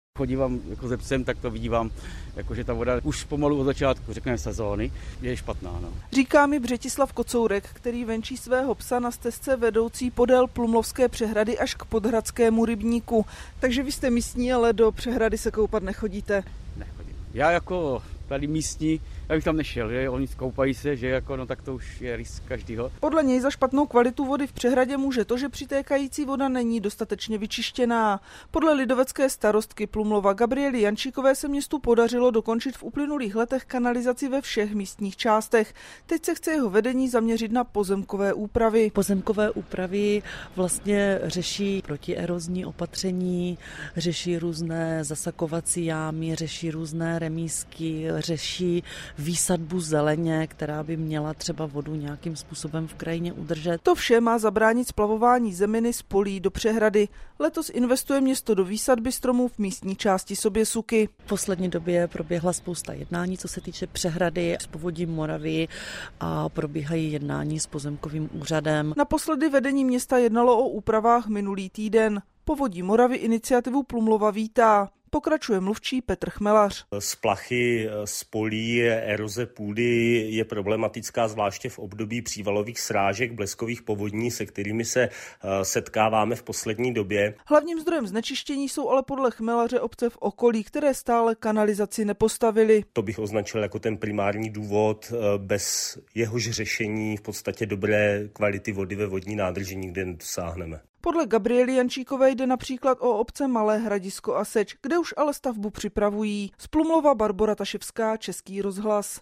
Přehlídka barevných krojů či neobvyklých hudebních nástrojů. Takový byl festival Folkmáj v Javorníku - 08.07.2024